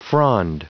Prononciation du mot frond en anglais (fichier audio)
Prononciation du mot : frond